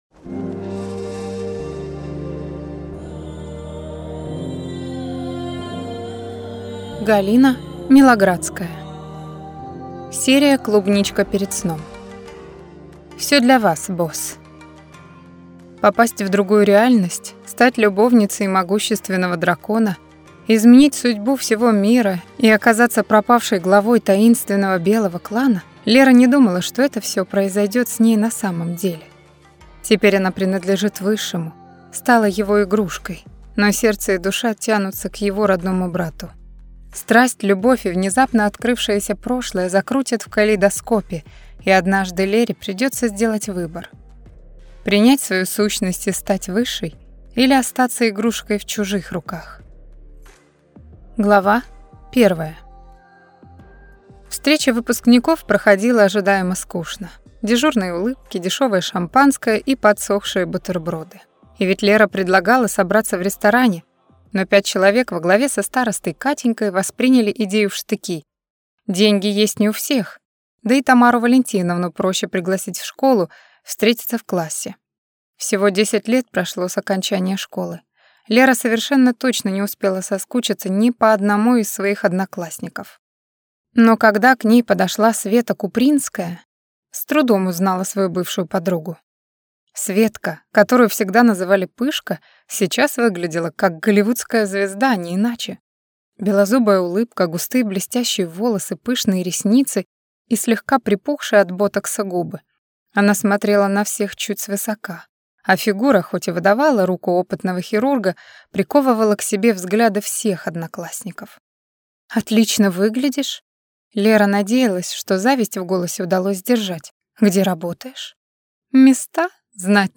Аудиокнига Всё для вас, Босс!